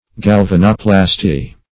Search Result for " galvanoplasty" : The Collaborative International Dictionary of English v.0.48: Galvanoplasty \Gal*van"o*plas`ty\, n. [Cf. F. galanoplastie.]